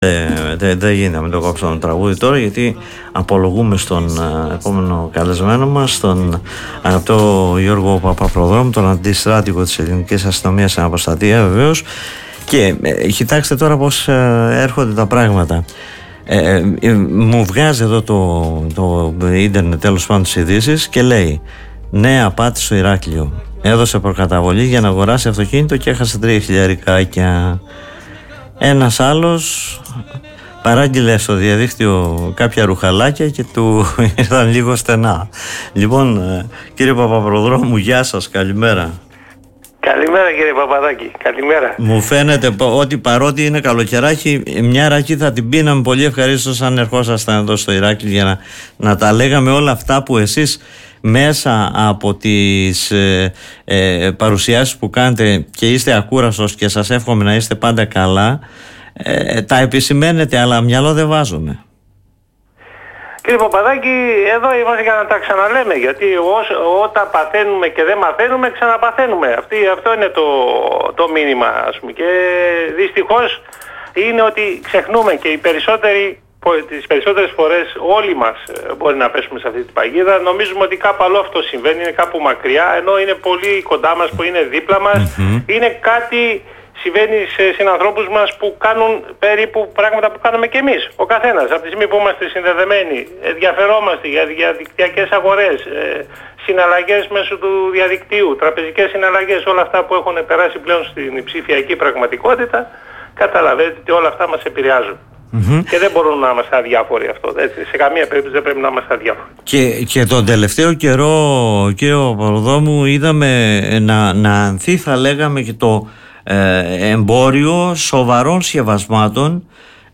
μίλησε στην εκπομπή “Δημοσίως”